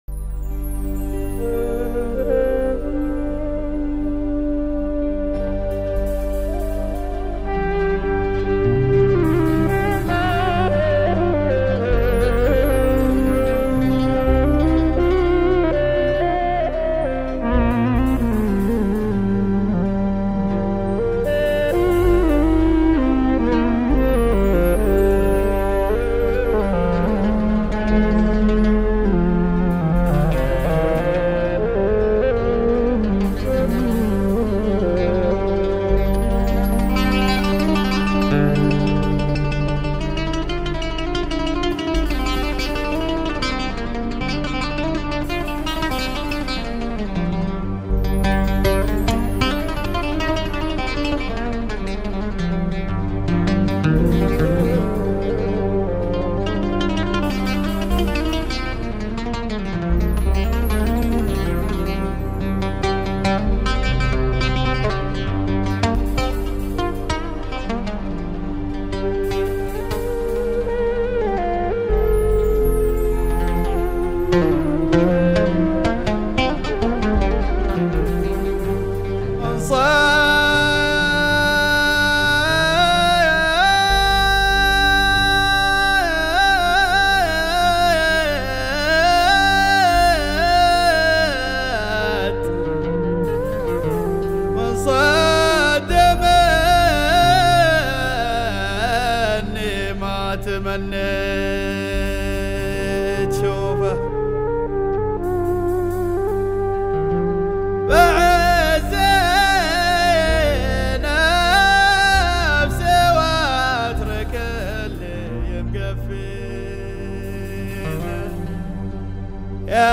شعبيات